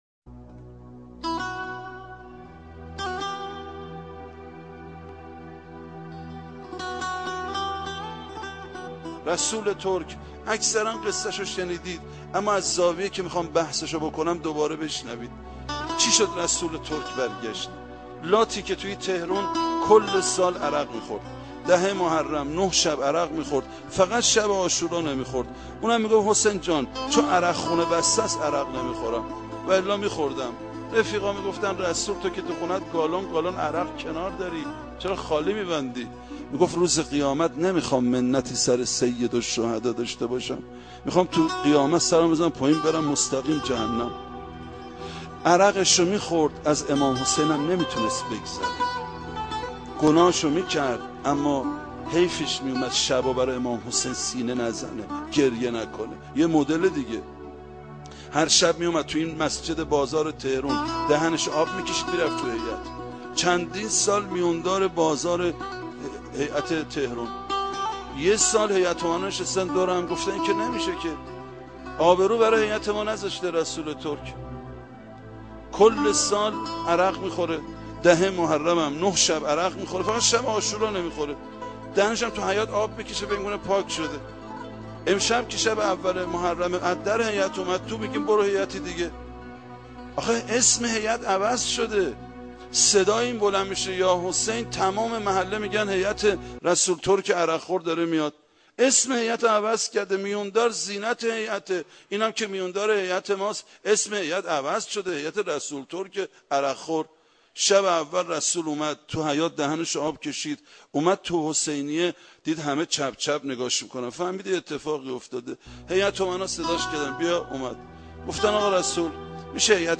سخنرانی ویژه محرم